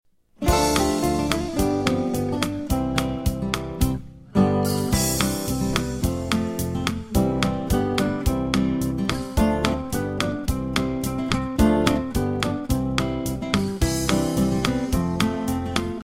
Listen to a sample of the instrumental version.